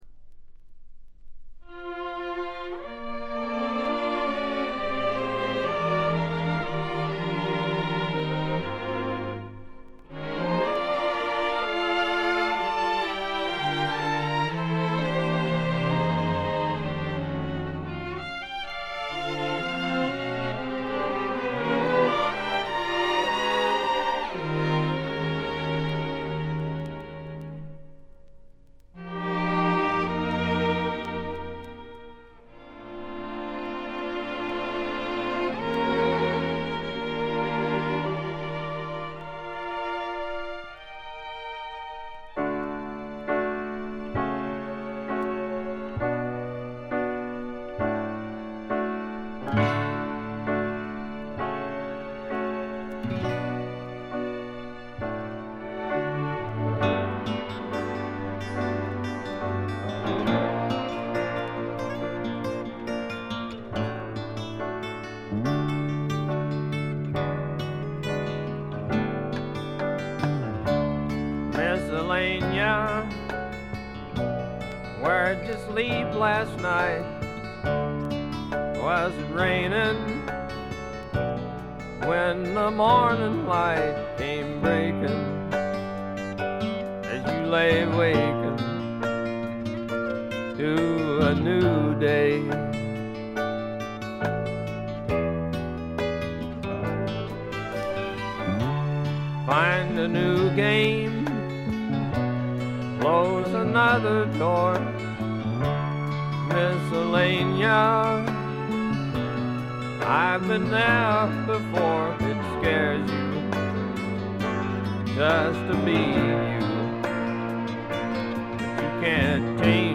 部分試聴ですが、ところどころでチリプチ、散発的なプツ音少し。
いかにもテキサス／ダラス録音らしいカントリー系のシンガー・ソングライター作品快作です。
ヴォーカルはコクがあって味わい深いもので、ハマる人も多いと思いますね。
試聴曲は現品からの取り込み音源です。
Recorded At - January Sound Studio